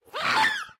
Sound / Minecraft / mob / ghast / scream3.ogg
scream3.ogg